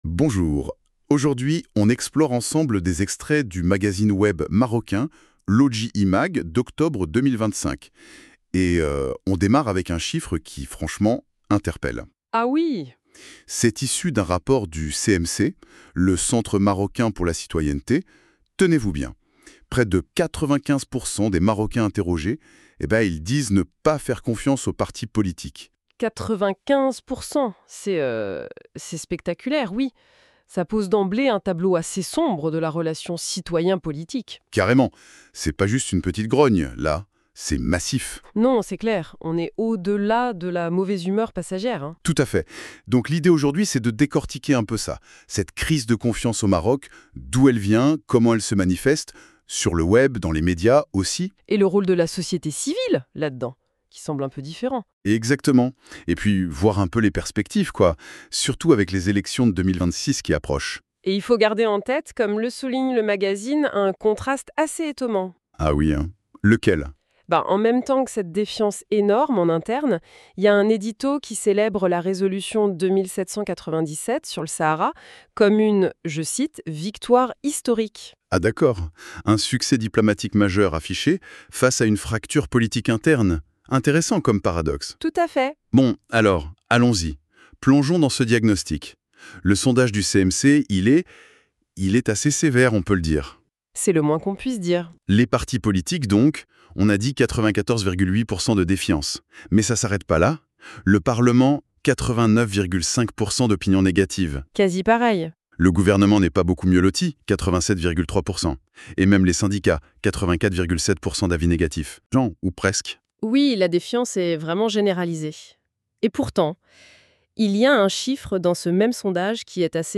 Podcast - débat I-MAG N49.mp3 (14.11 Mo)